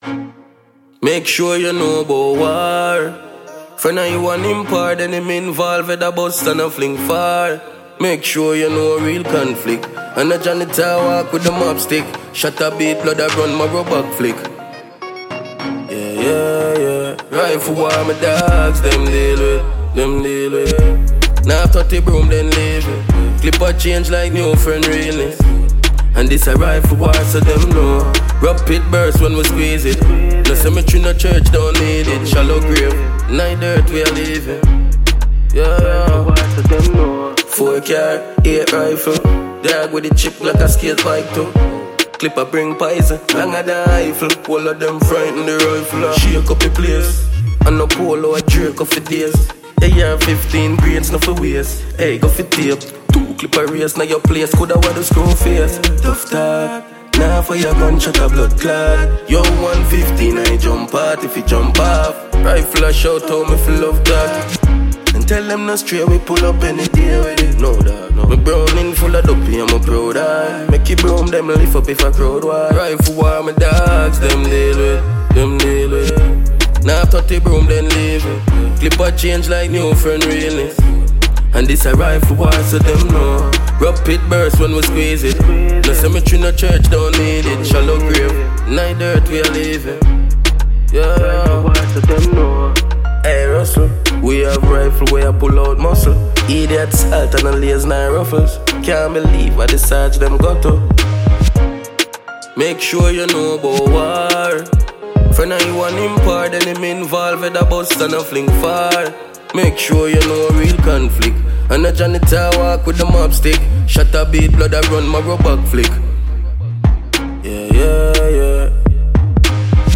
Jamaican dancehall artist